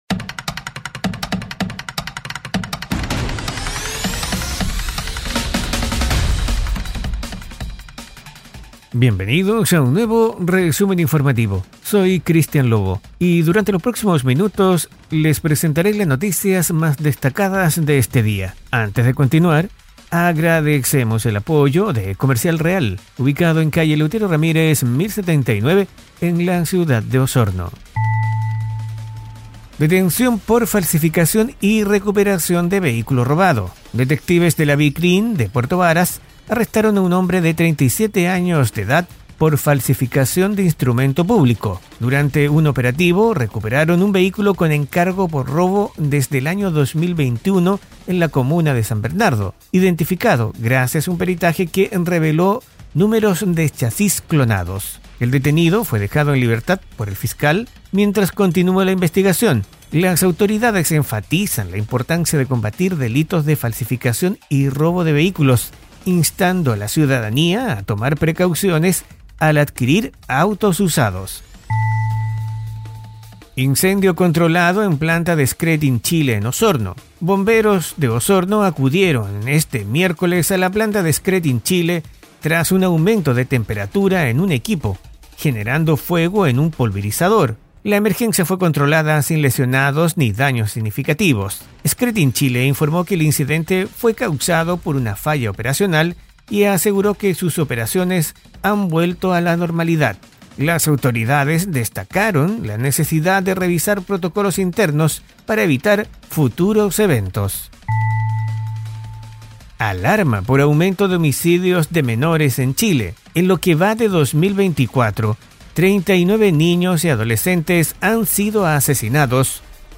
Resumen Informativo 🎙 Podcast 28 de noviembre de 2024